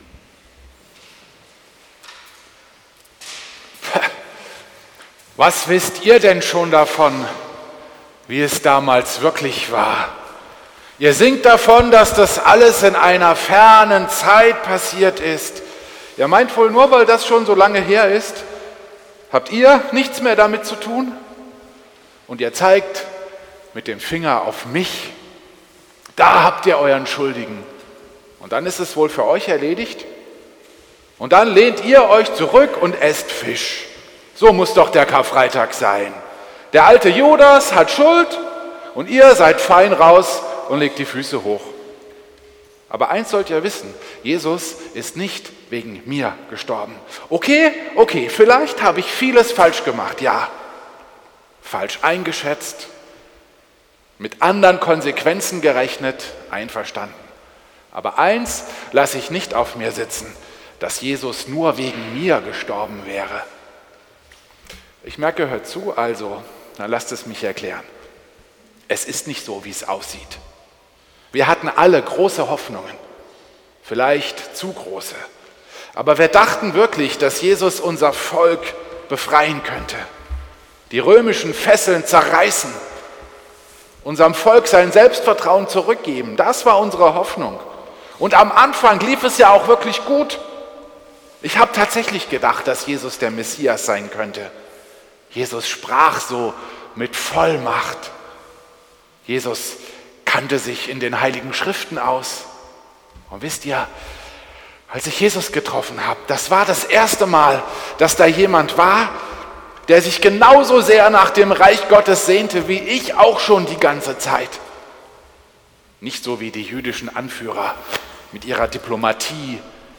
Predigt am Karfreitag